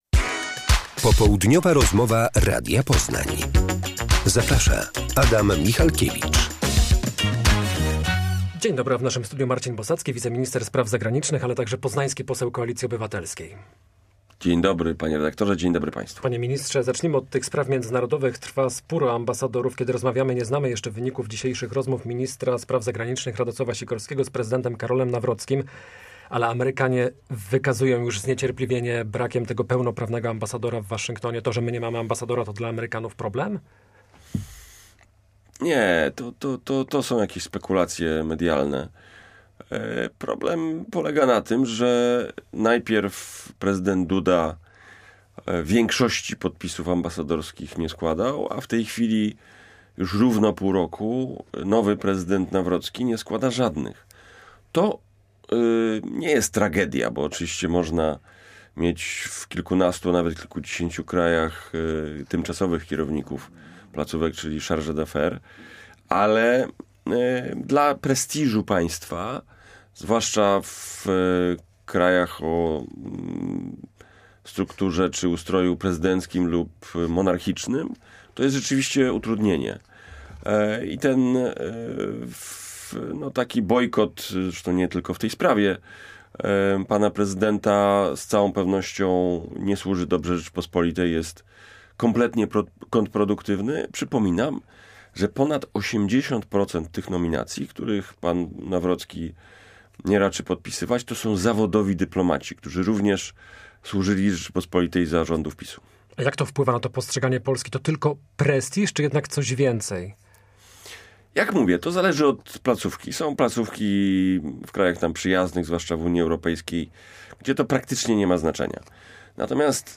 Popołudniowa rozmowa Radia Poznań – Marcin Bosacki
Gościem Popołudniowej Rozmowy Radia Poznań jest wiceminister spraw zagranicznych i poseł Koalicji Obywatelskiej Marcin Bosacki.